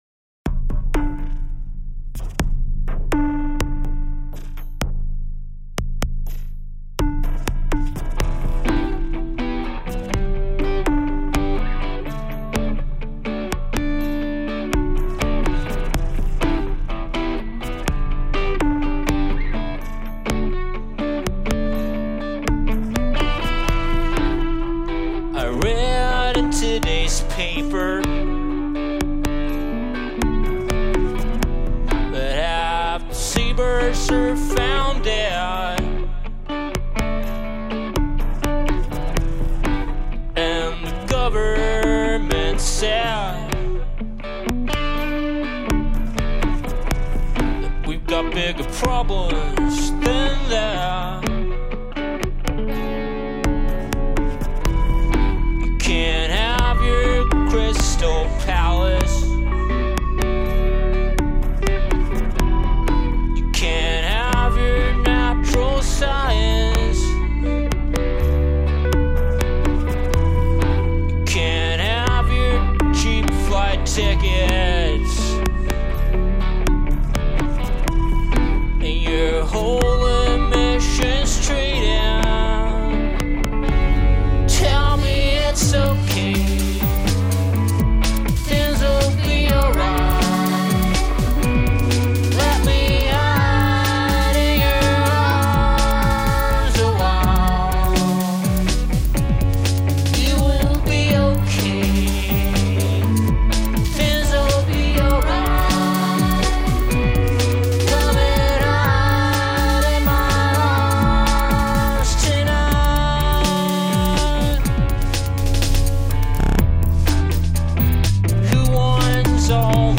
was recorded live in our studio on May 18-20, 2007.
lead vocals, guitar, harmonica, short wave radio
drums, percussion, beats
saxophone
synthesizer